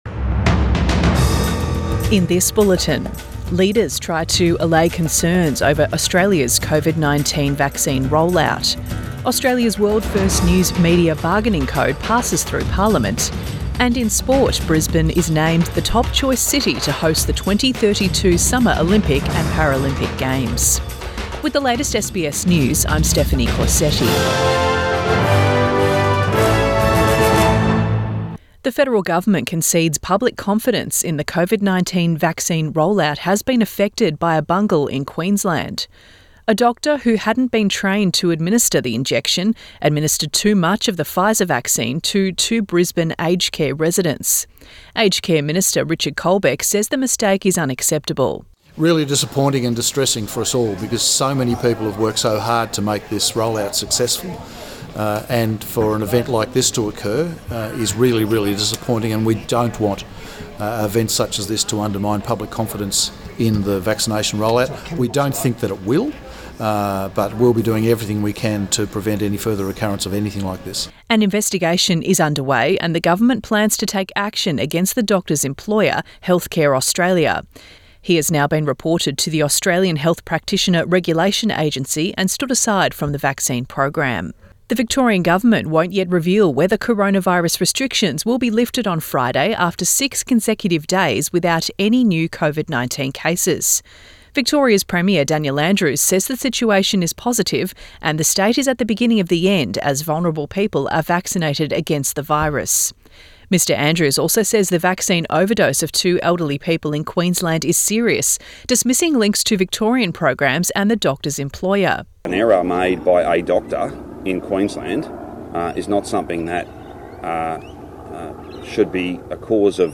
Midday bulletin 25 February 2021